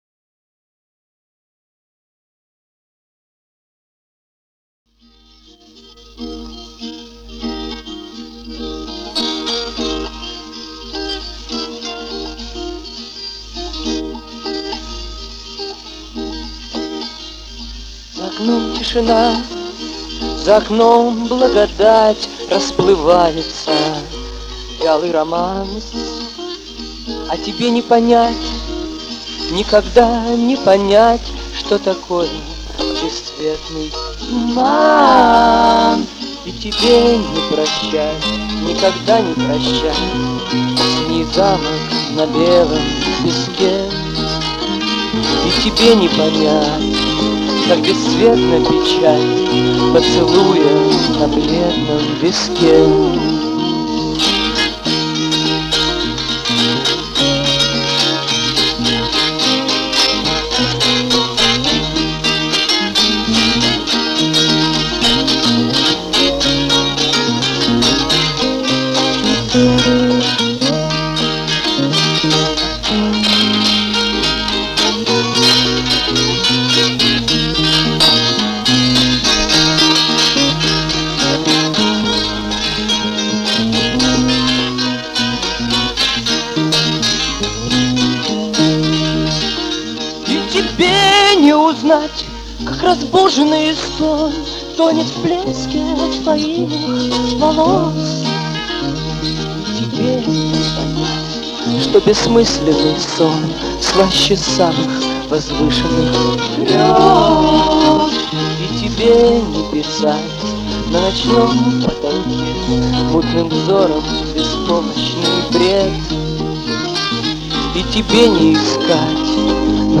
Но в 1972 году я начал сам записывать свои песни для моей подружки, которая жила в Риге.
В моем архиве есть магнитоальбом, на котором записана песня «Туман» — единственная, в которой участвуют четыре человека из «Апреля».